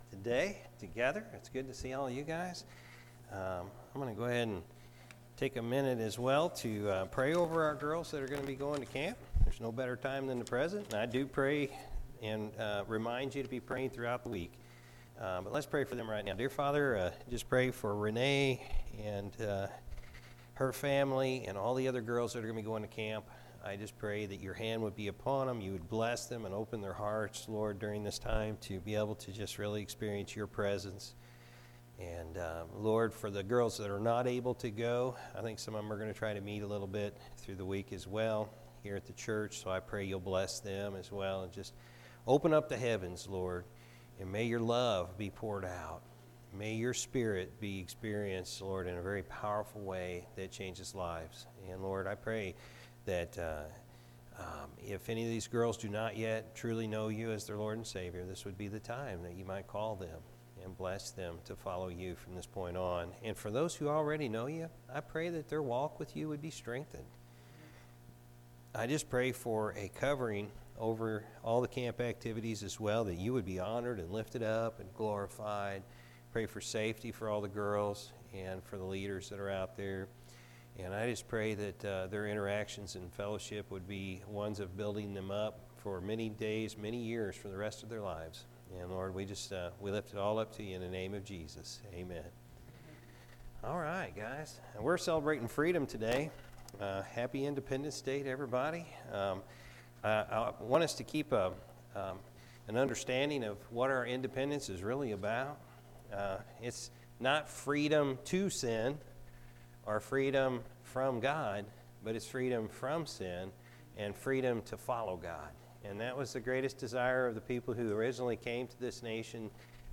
July-4-2021-morning-service.mp3